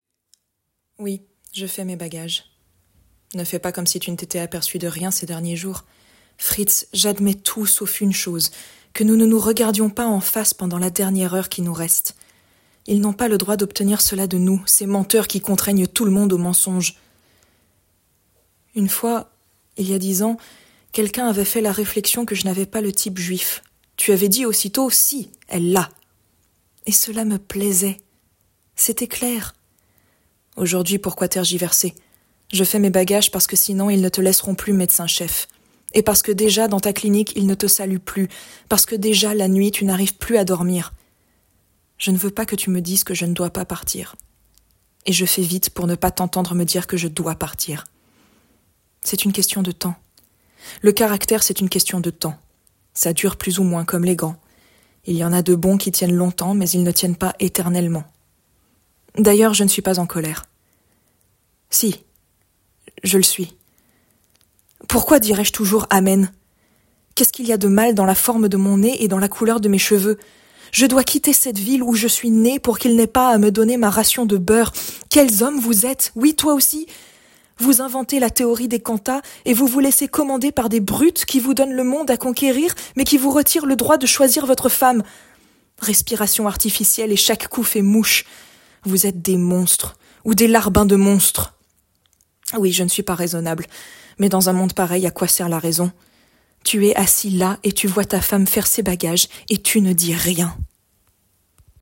Monologue de la Femme Juive - Brecht, Grand' Peur et Misère du IIIè Reich
21 - 48 ans - Contralto